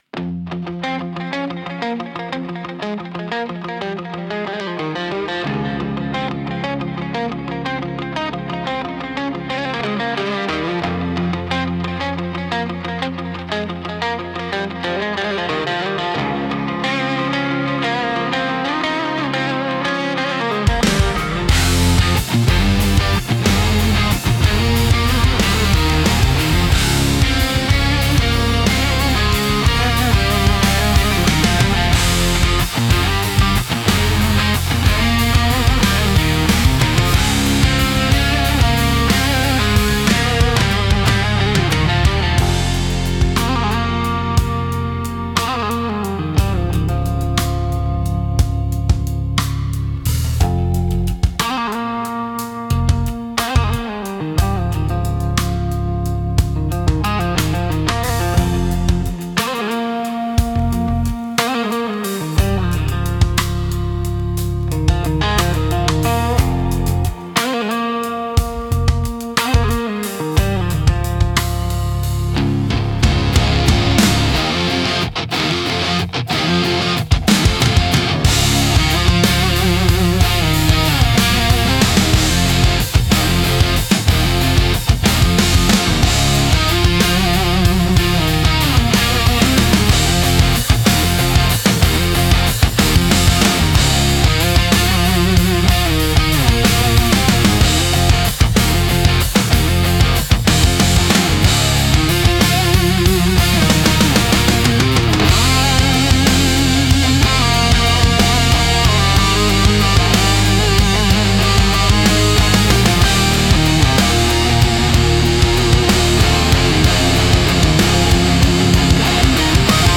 Instrumental - The Long Road In 4.22